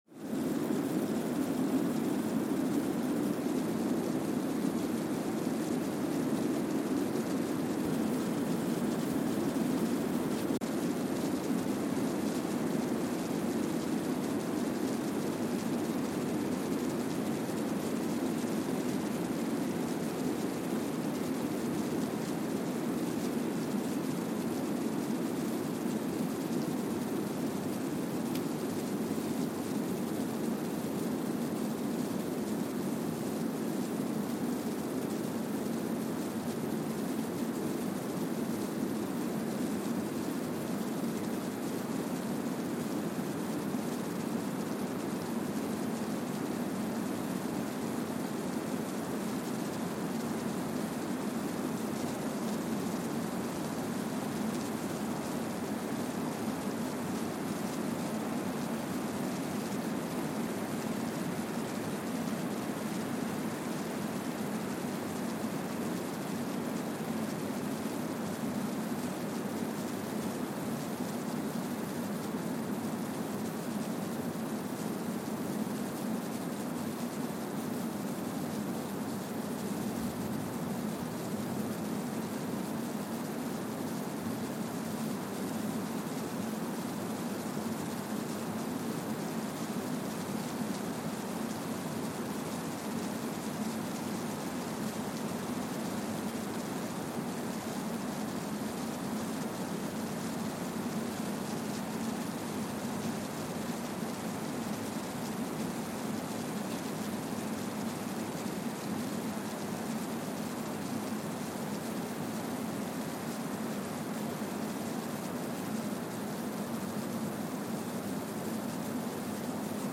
Kwajalein Atoll, Marshall Islands (seismic) archived on November 1, 2020
No events.
Sensor : Streckeisen STS-5A Seismometer
Speedup : ×1,000 (transposed up about 10 octaves)
Loop duration (audio) : 05:45 (stereo)
SoX post-processing : highpass -2 90 highpass -2 90